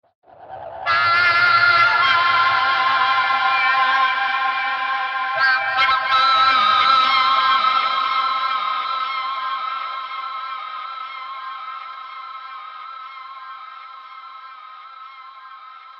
遥远的世界末日警报
描述：带有混响的闹钟
标签： 混响远的启示 报警
声道立体声